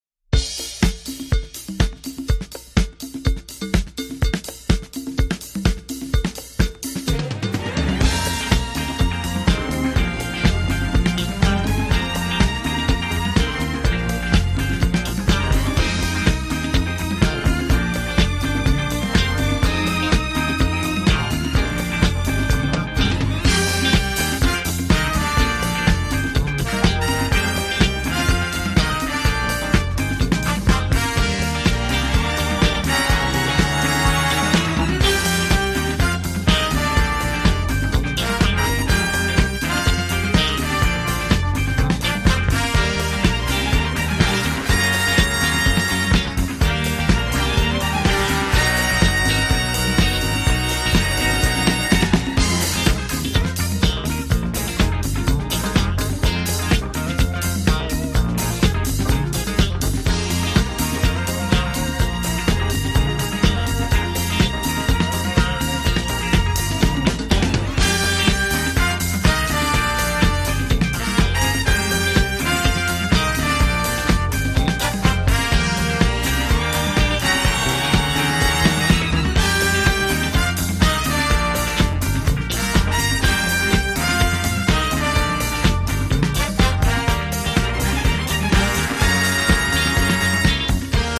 The breezy, Philly soul-tinged
presents an easy-glide funk that's just irresistible.